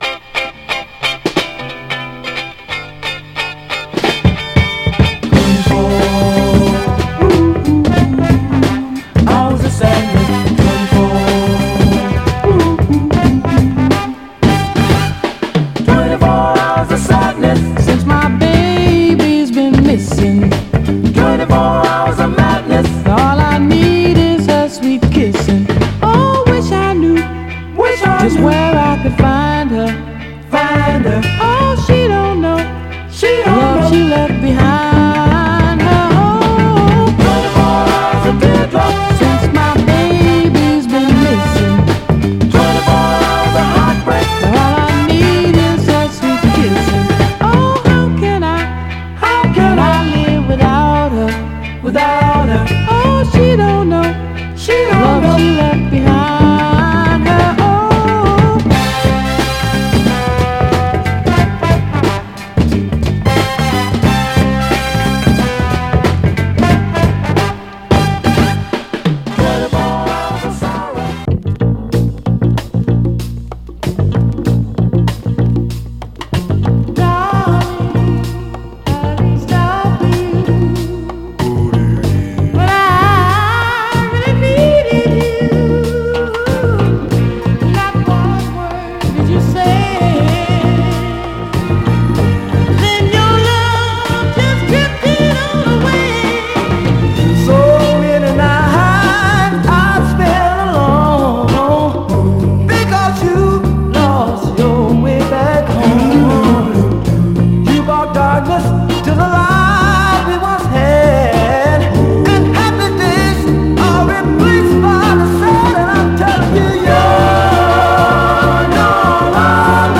軽快な甘茶ミッド・ダンサー
※試聴音源は実際にお送りする商品から録音したものです※